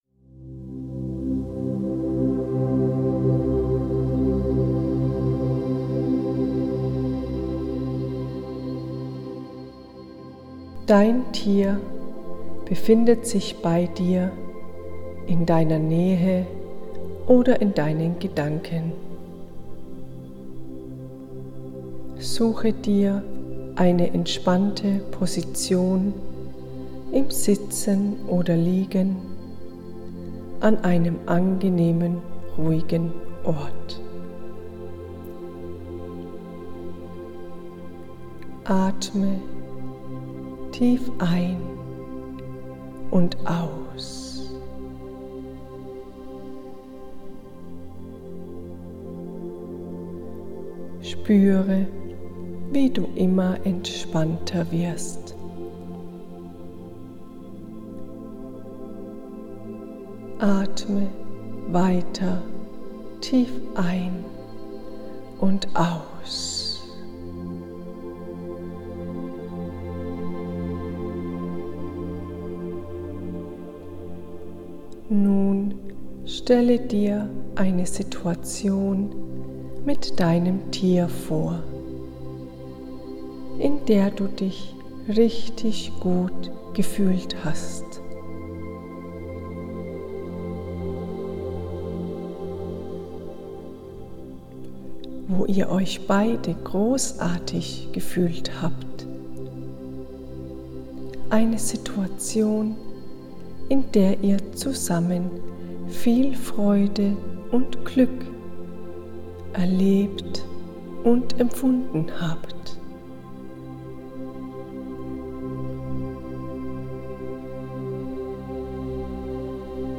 Kurz-Meditation: Ein besonderer Moment mit Deinem Tier (kostenfrei zum Kennenlernen)